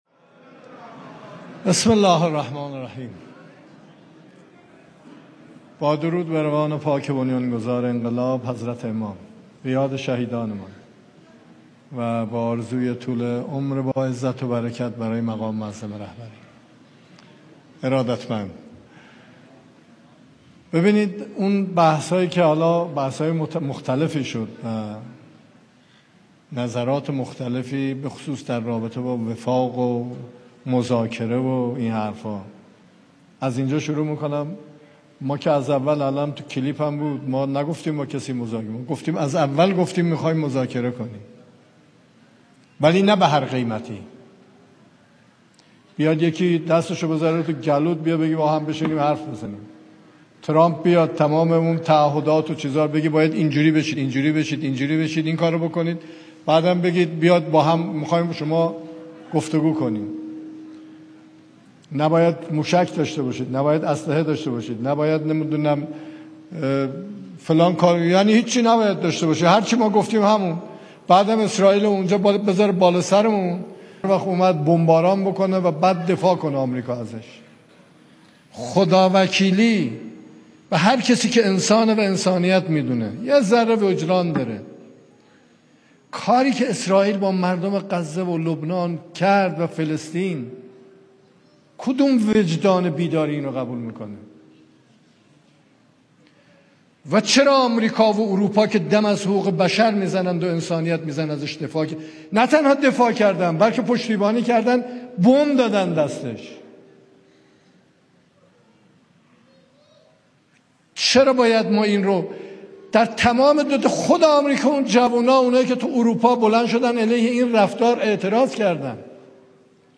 صدا | سخنان رییس جمهور در جمع فرهیختگان و نخبگان غرب استان تهران